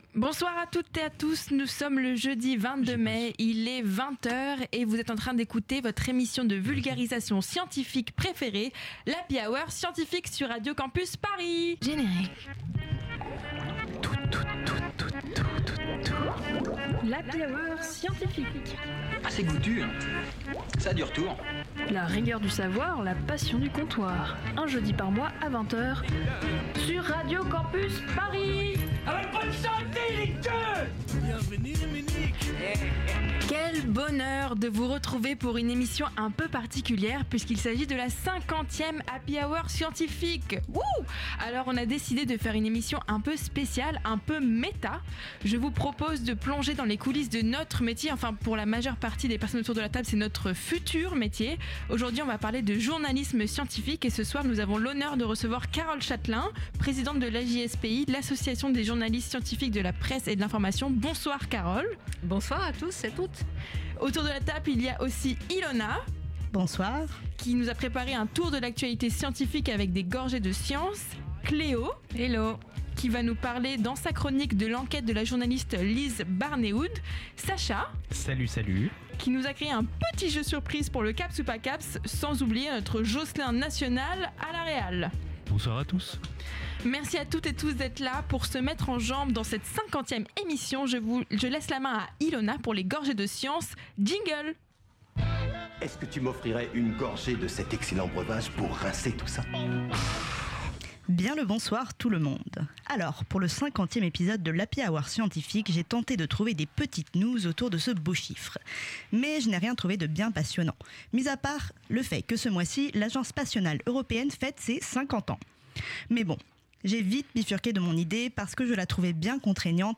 Partager Type Magazine Sciences jeudi 22 mai 2025 Lire Pause Télécharger Quel bonheur de vous retrouver pour une émission un peu particulière puisqu'il s’agit du 50ème Happy Hour Scientifique.